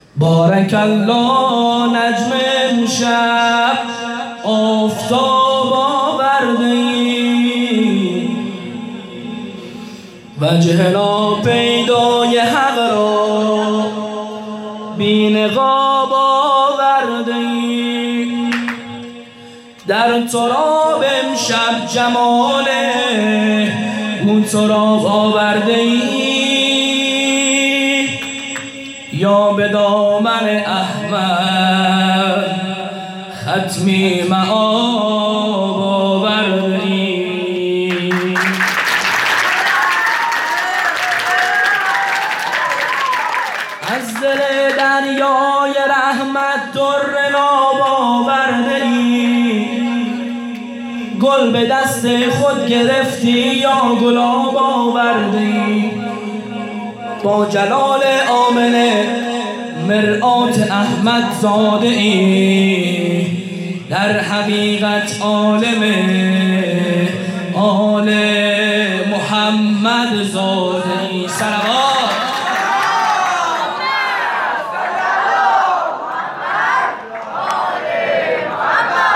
6-مدح-بارک-الله-نجمه-امشب-آفتاب-آورده-ای.mp3